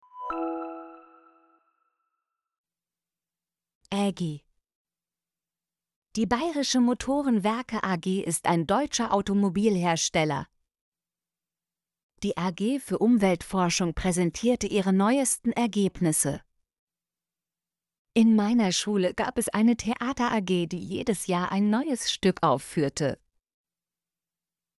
ag - Example Sentences & Pronunciation, German Frequency List